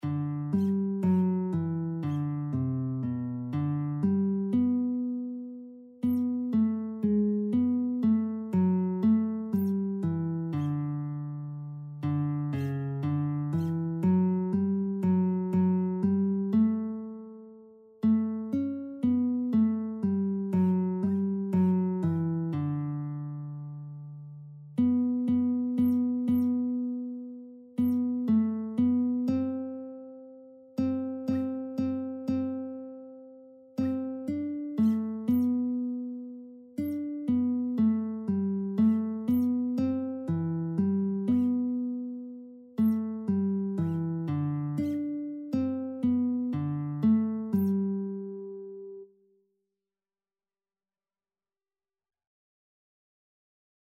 G major (Sounding Pitch) (View more G major Music for Lead Sheets )
6/4 (View more 6/4 Music)
Classical (View more Classical Lead Sheets Music)